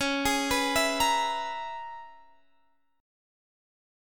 Listen to C#m13 strummed